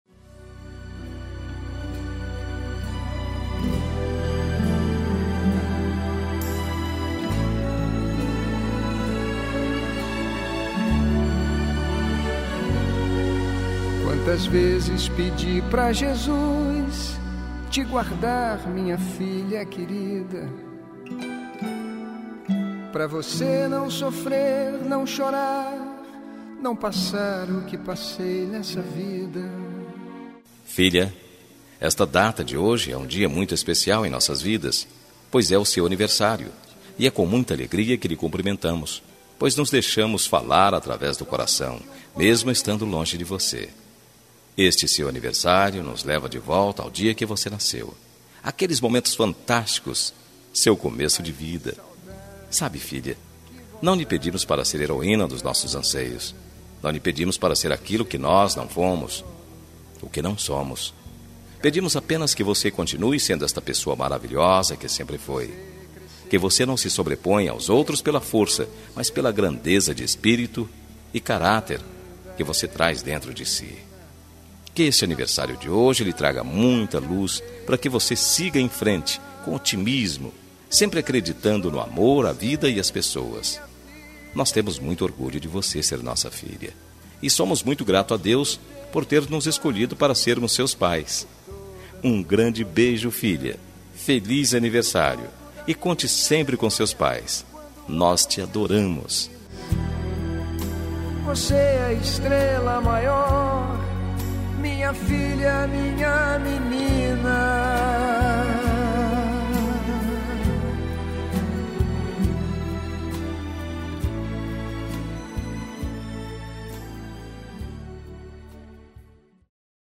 Telemensagem de Aniversário de Filha – Voz Masculina – Cód: 1803 – Distante/Plural